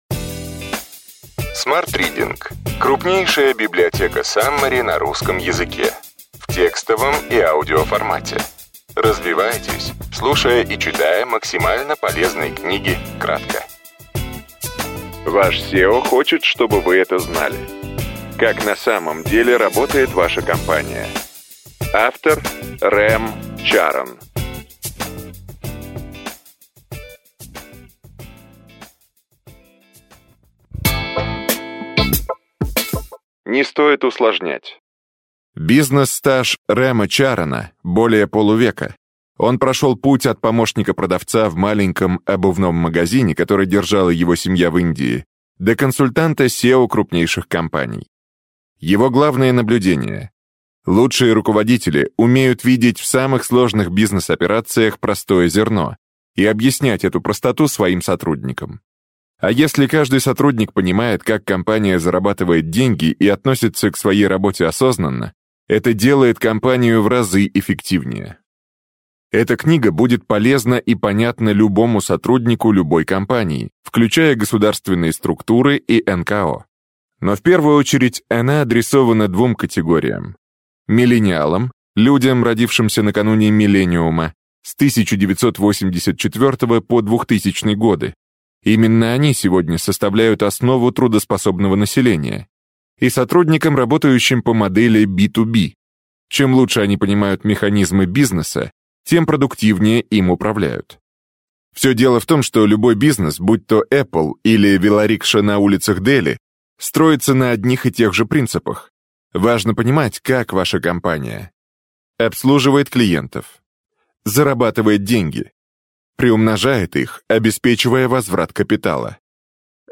Аудиокнига Ваш CEO хочет, чтобы вы это знали. Как на самом деле работает ваша компания.